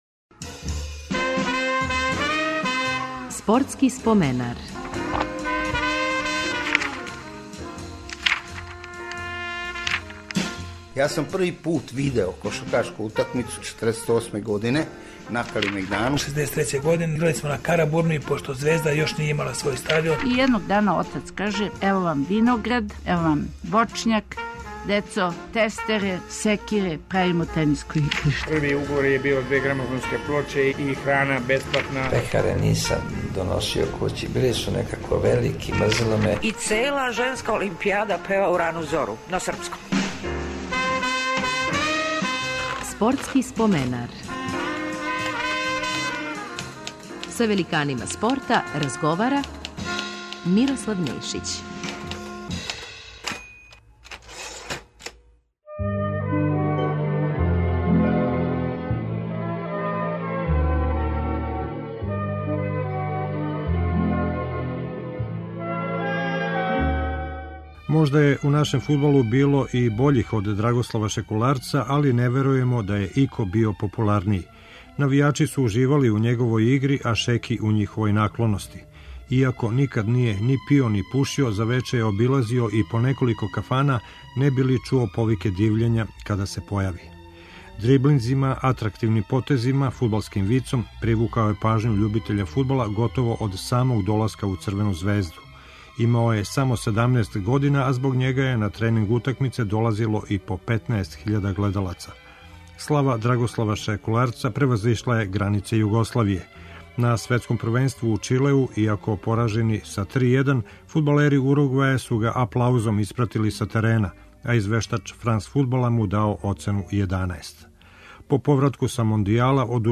Репризираћемо емисију у којој је гост био Драгослав Шекуларац, легенда југословенског и српског фудбала, и, можда и пре свега, Црвене звезде.